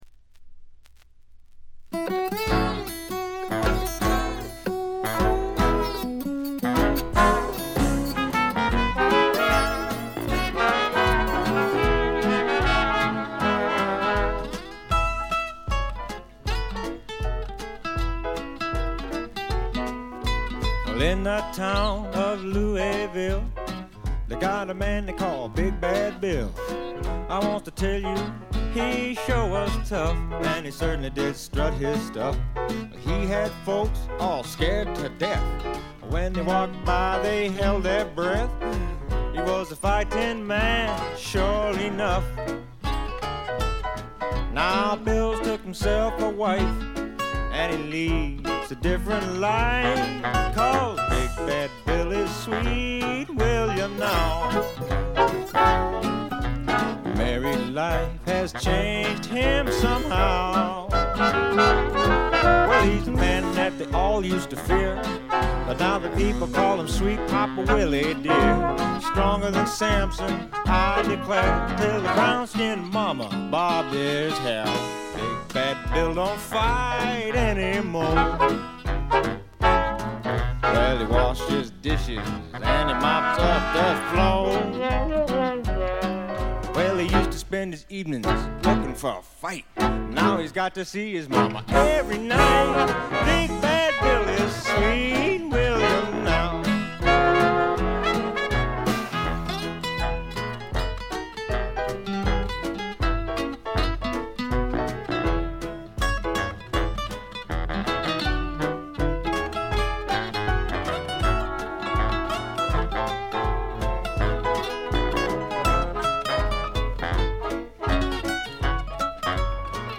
ディスク：部分試聴ですが、静音部での軽微なチリプチ少し。
試聴曲は現品からの取り込み音源です。